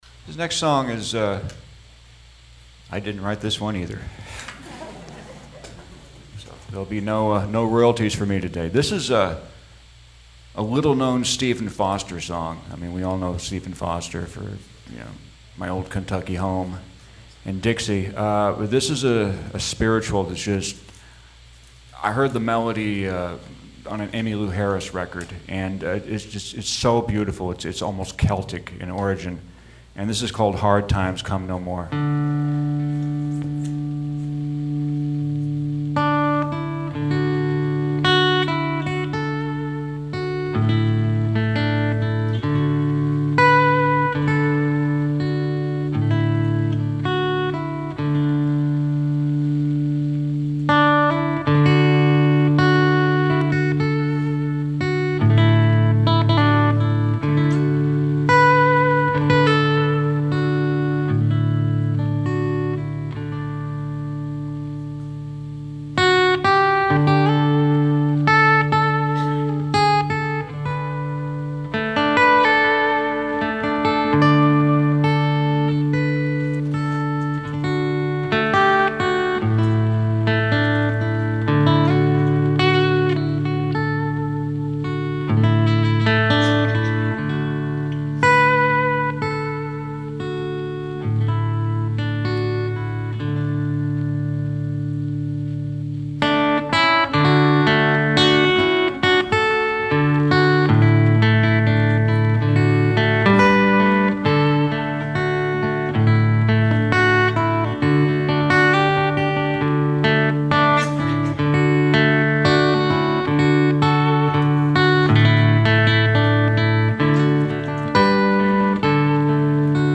All songs recorded live in concert.
and says it all without a word.